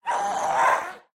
ghast
scream5.ogg